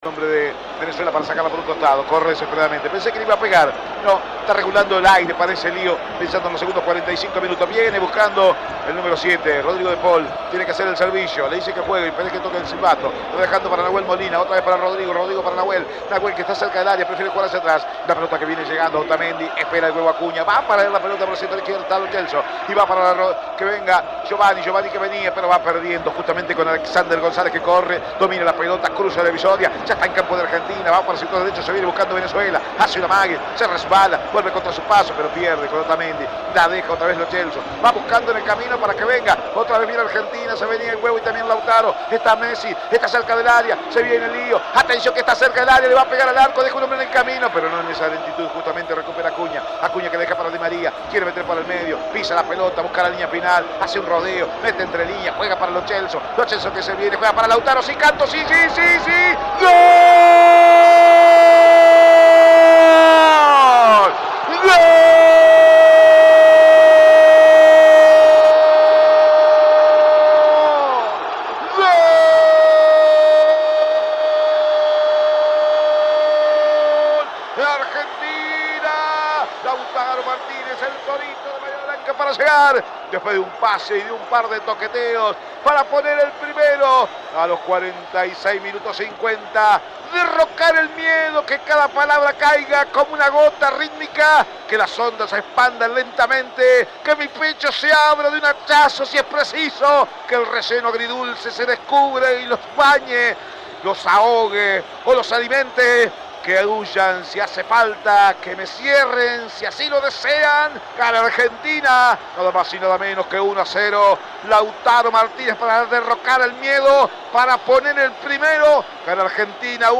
Escuchá los goles de la Selección en la voz
GOL-DE-ARGENTINA-01-EDITADO-.mp3